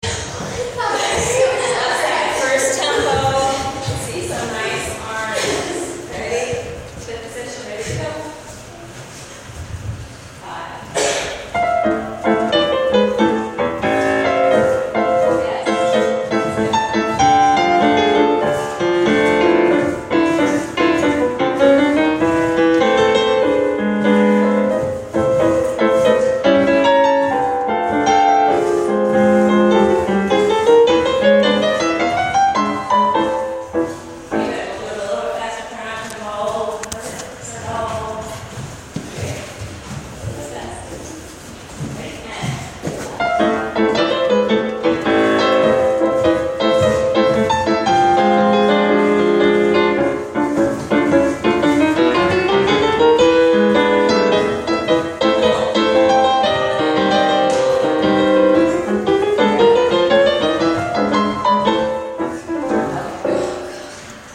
Kinda spooky 6/8 for Ballet class
Lots of what I play for Ballet class is improvised. This is an example.